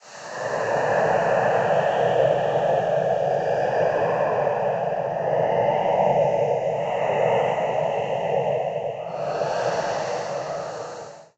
beyond/Assets/Sounds/Enemys/ghost.ogg at unity6
ghost.ogg